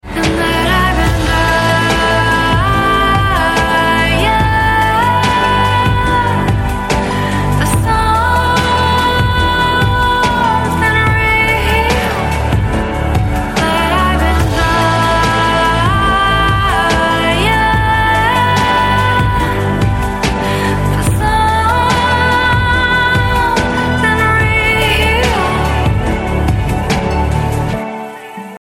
• Качество: 128, Stereo
поп
грустные
спокойные
красивый женский голос
Chill